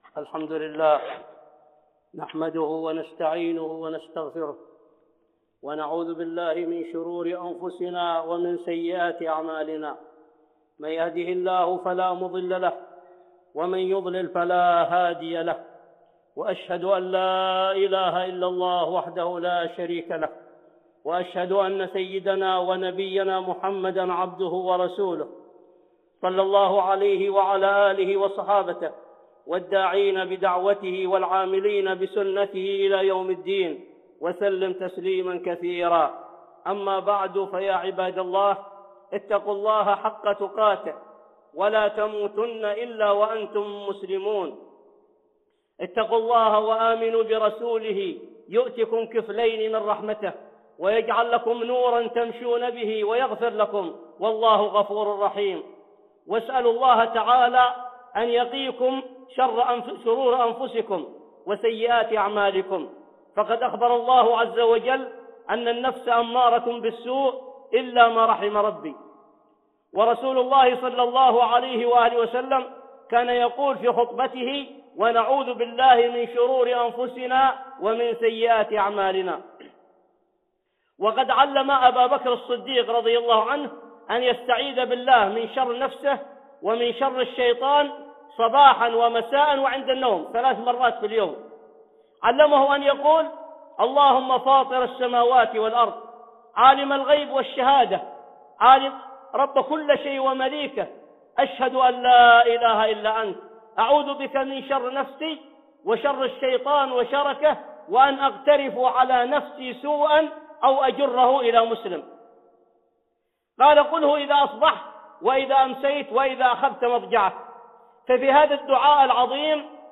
(خطبة جمعة) بعنوان (الملعونون) والثانية (العوانس)